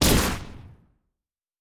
Sci Fi Explosion 16.wav